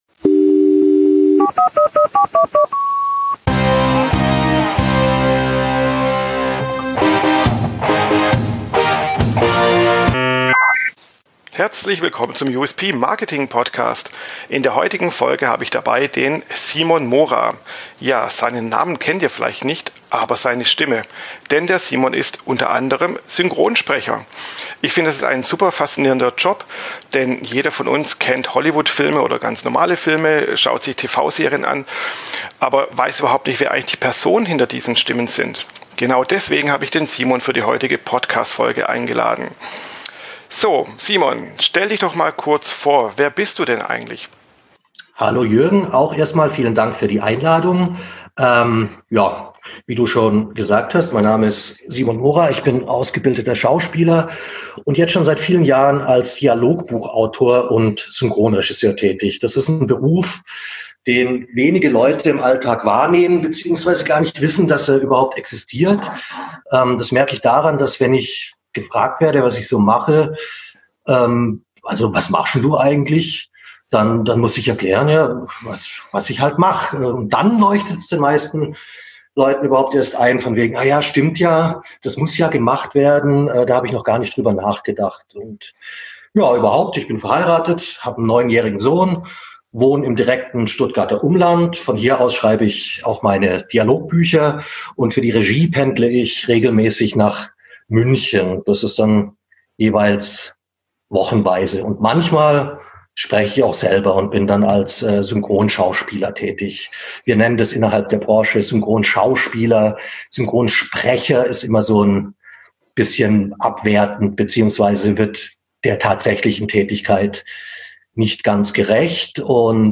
In dem Interview-Format erzählen die Gäste, wie sie den USP (Unique Selling Point) ihres Unternehmens fanden, schärften, veränderten.